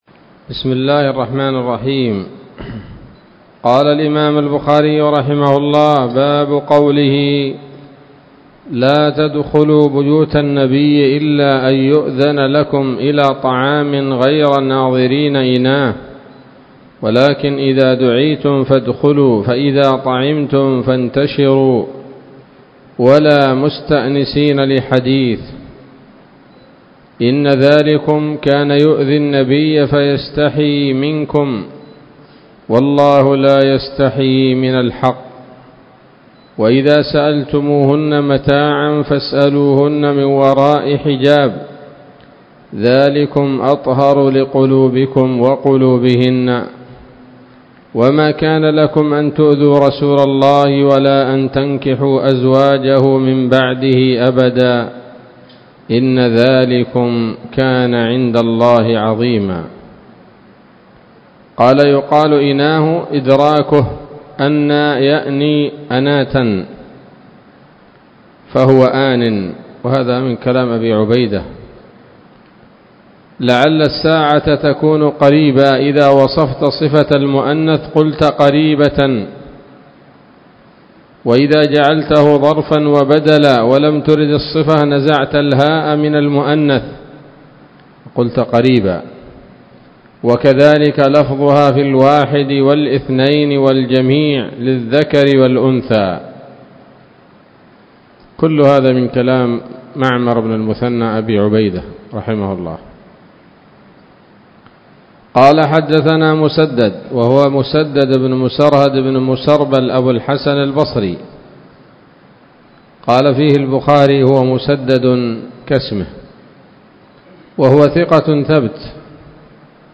الدرس السادس بعد المائتين من كتاب التفسير من صحيح الإمام البخاري